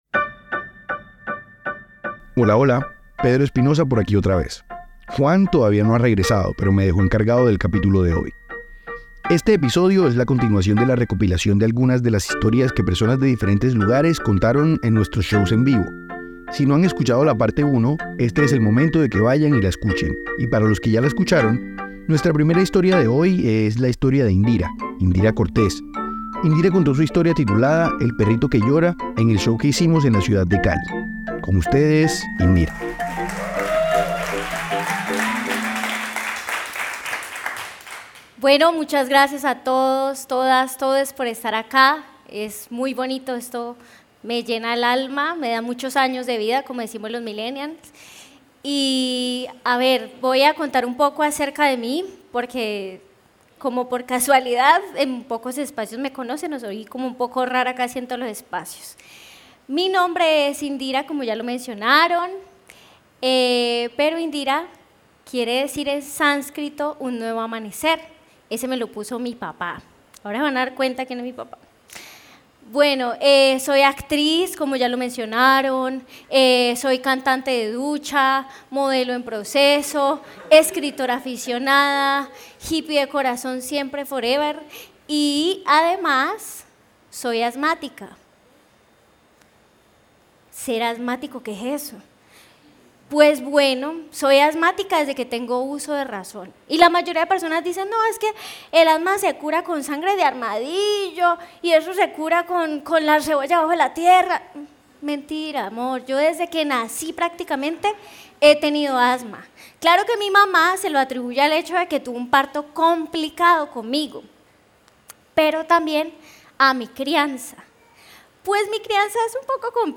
Un recorrido por las historias más destacadas de los shows de storytelling de Bogotá, Cali y Palenque.